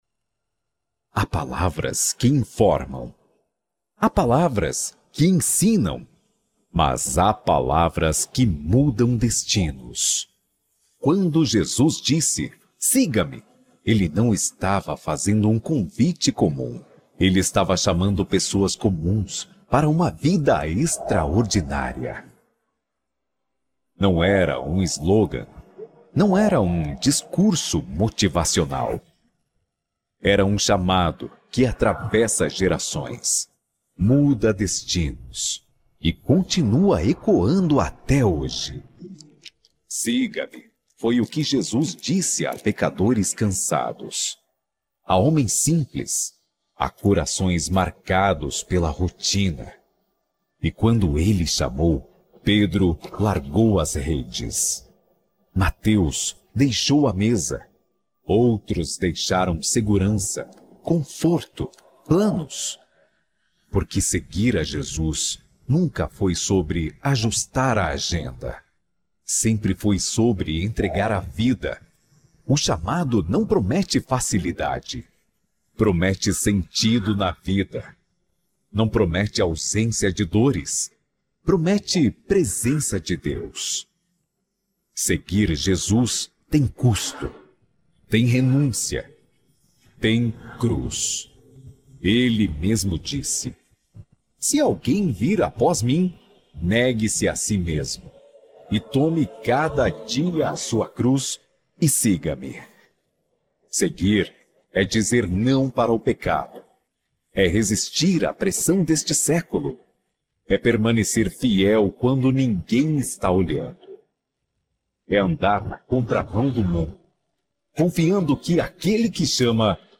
NARRAÇÃO :